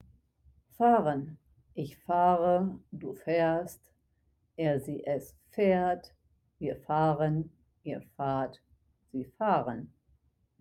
to go (car, train) fahren (FAHR-en)